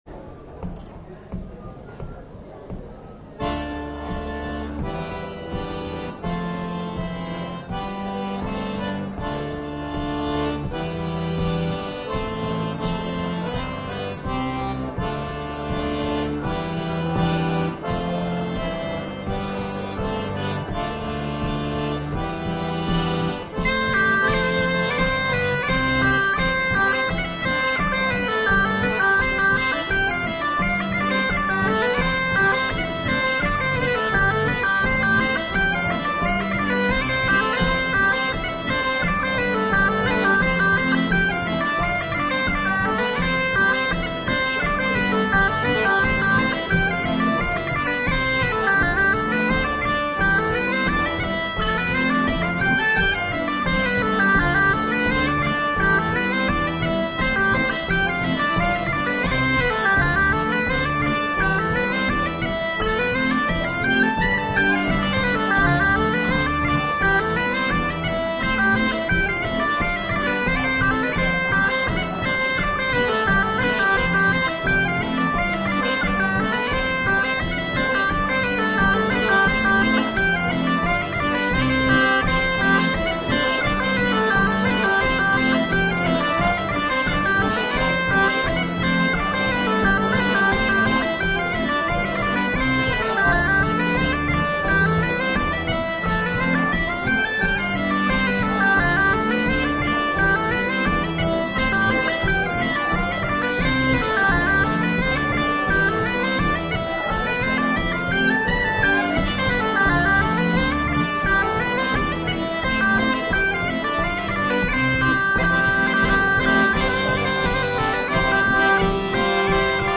Registrazioni live: